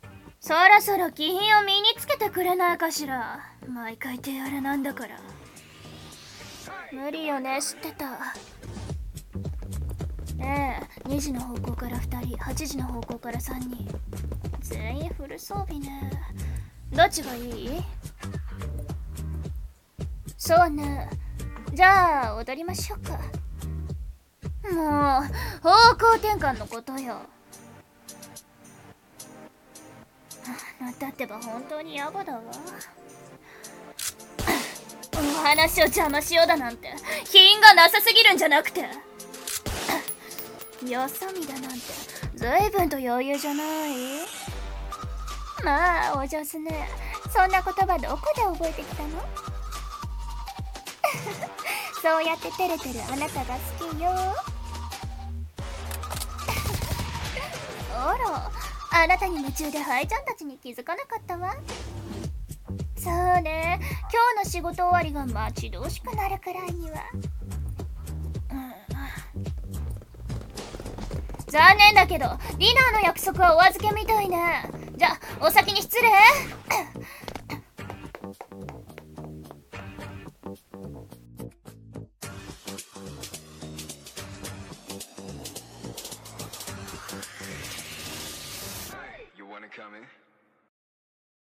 声劇】殺し屋たちのワルツ